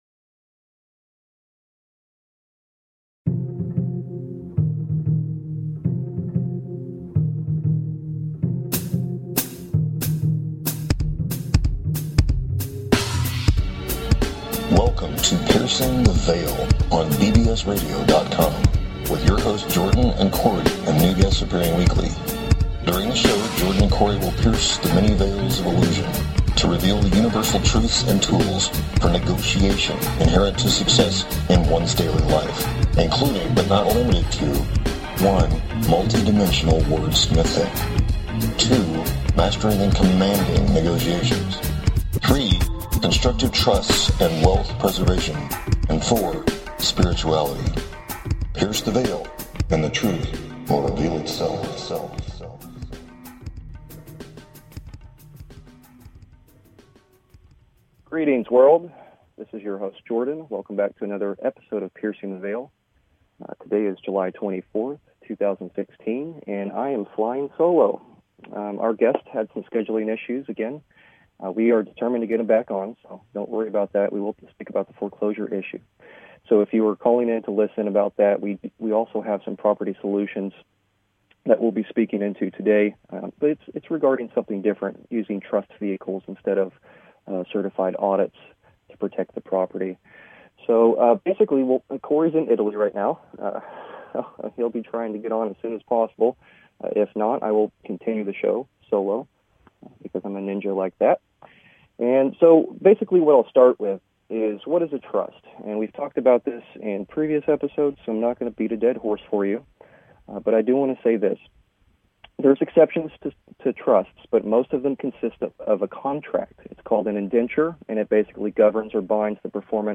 Talk Show Episode
No guest this week.